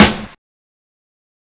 Snare
Original creative-commons licensed sounds for DJ's and music producers, recorded with high quality studio microphones.
verby-snare-sample-g-sharp-key-208-mPP.wav